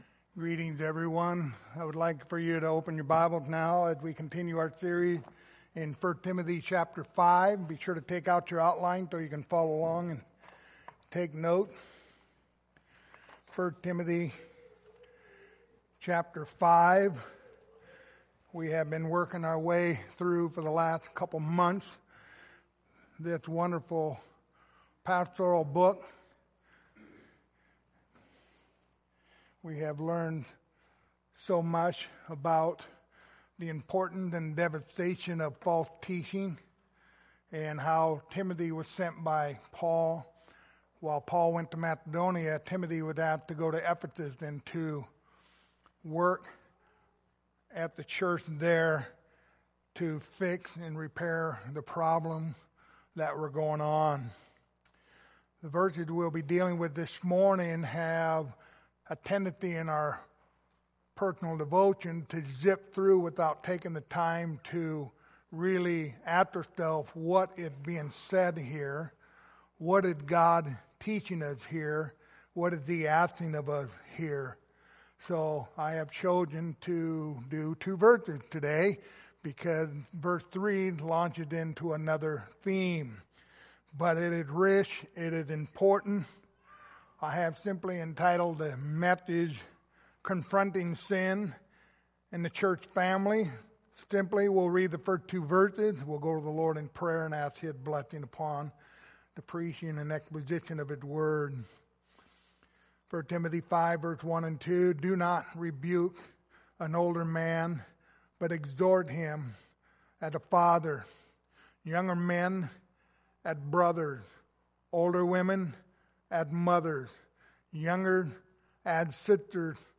Passage: 1 Timothy 5:1-2 Service Type: Sunday Morning